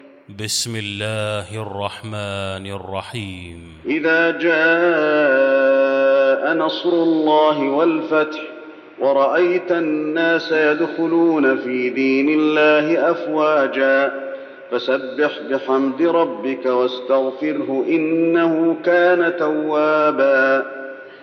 المكان: المسجد النبوي النصر